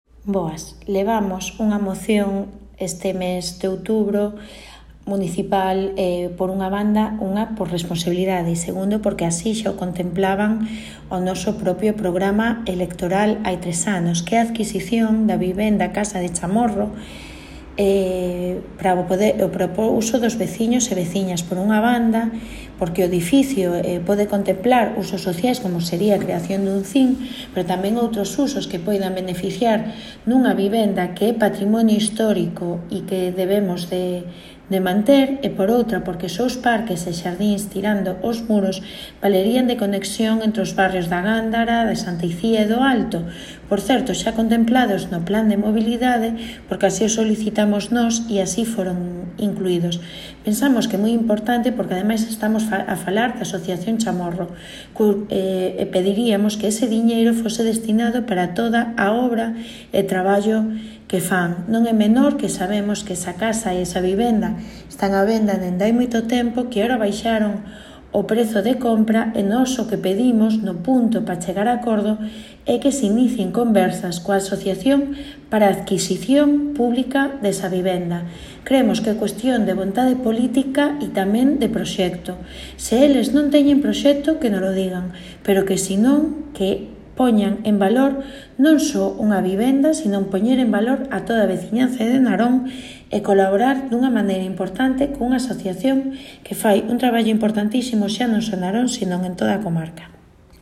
Adxúntase as declaración da portavoz municipal del BNG de Narón, Olaia Ledo
BNG-Narón-antigo-colexio-Chamorro-declaración-Ledo.mp3